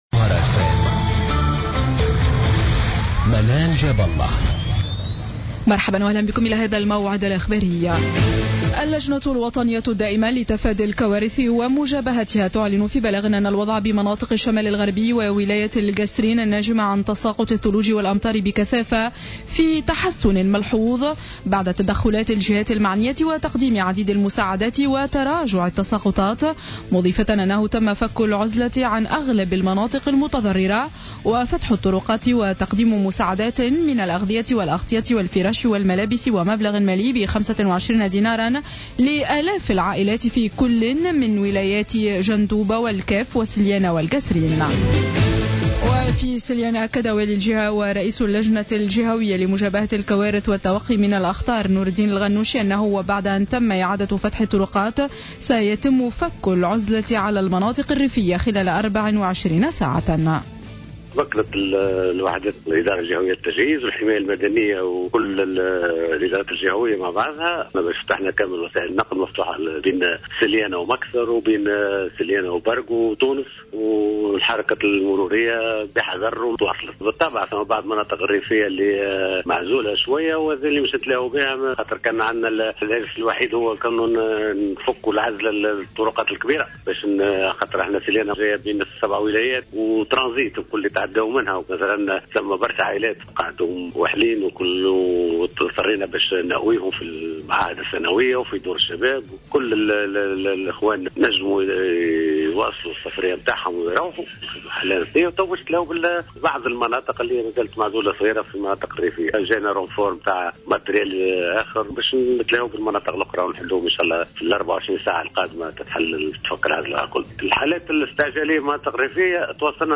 نشرة أخبار منتصف الليل ليوم الجمعة 02-01-15